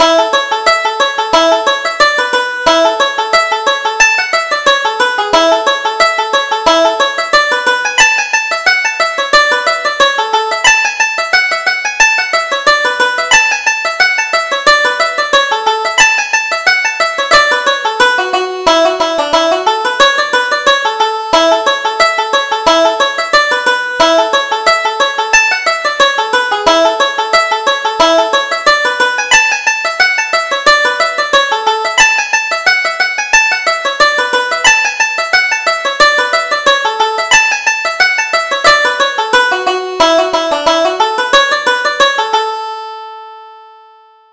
Reel: An Ugly Customer